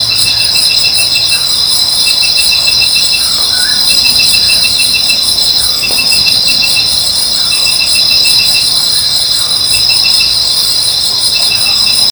Atajacaminos Ocelado (Nyctiphrynus ocellatus)
Nombre en inglés: Ocellated Poorwill
Localidad o área protegida: Bio Reserva Karadya
Condición: Silvestre
Certeza: Vocalización Grabada